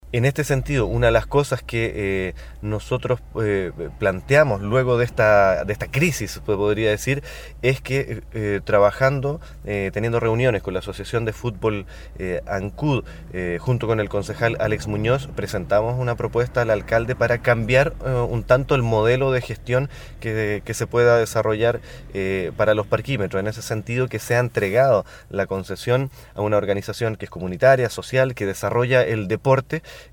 A partir de esta delicada situación, sostuvo Rodolfo Norambuena que junto al concejal Alex Muñoz han elaborado una propuesta que pretende que se haga  cargo de los parquímetros alguna entidad relacionada con el deporte.